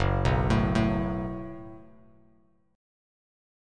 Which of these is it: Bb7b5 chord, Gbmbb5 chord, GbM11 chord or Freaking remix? Gbmbb5 chord